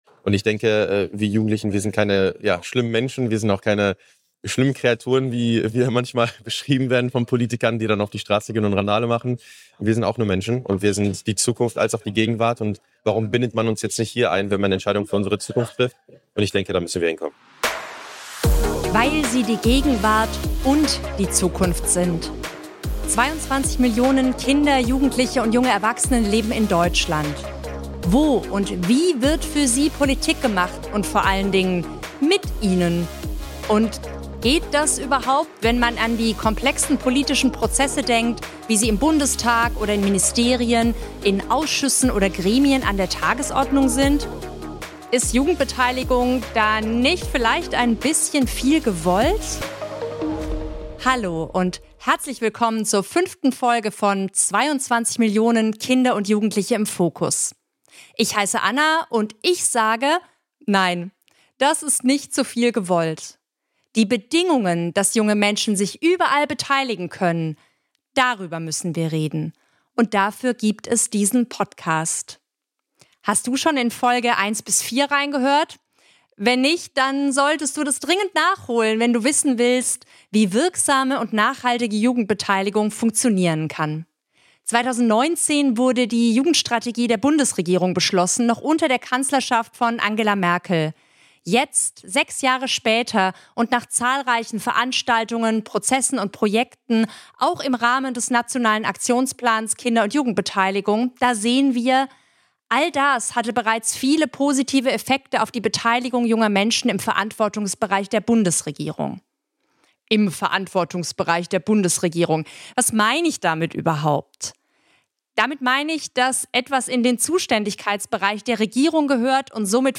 Zu Gast im Interview ist die Bundesjugendministerin Karin Prien, die einen Ausblick auf das gibt, was die Bundesregierung in Sachen Jugendbeteiligung in den kommenden Jahren plant.